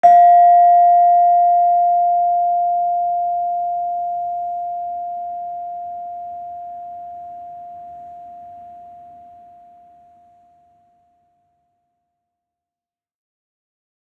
Gender-2-F4-f.wav